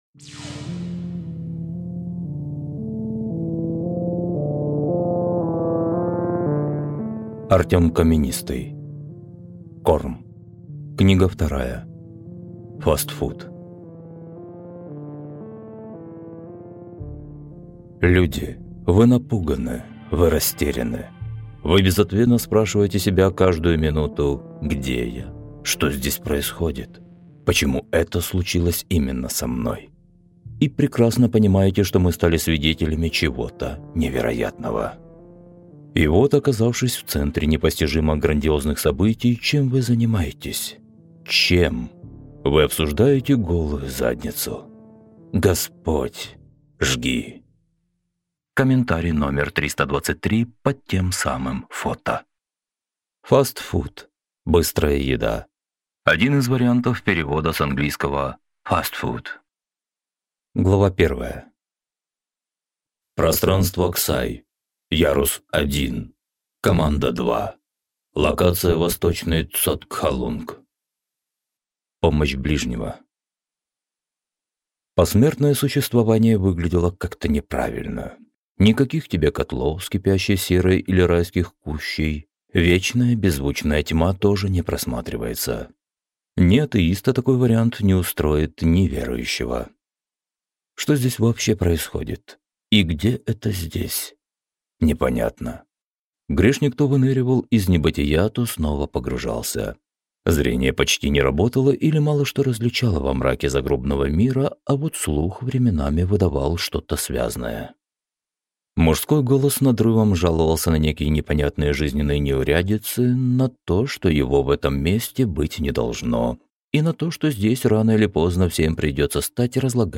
Аудиокнига Фастфуд - купить, скачать и слушать онлайн | КнигоПоиск